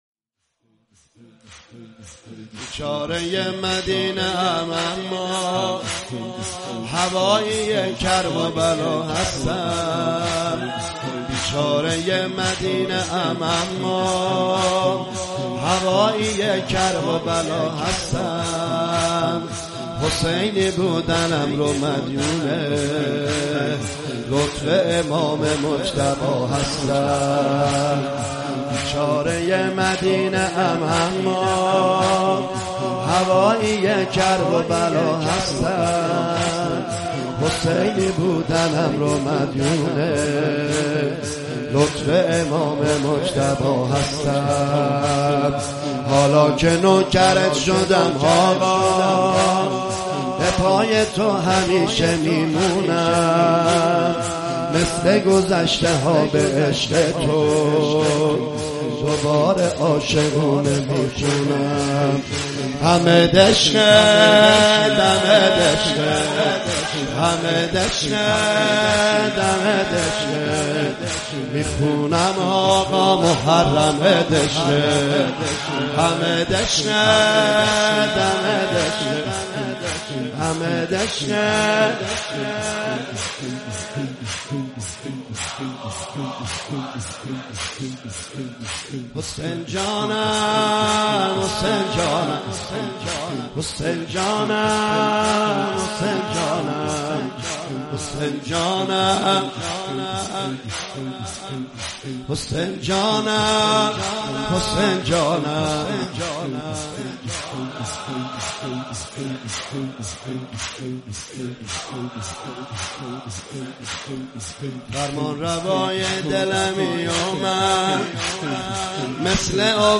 صوت مداحی های شب هفتم محرم سال ۱۳۹۷
فایل آن‌را از اینجا دانلود کنید: audio/mp3 واحد مرورگر شما از ویدئو پشتیبانی نمی‌کند. فایل آن‌را از اینجا دانلود کنید: audio/mp3 شور مرورگر شما از ویدئو پشتیبانی نمی‌کند.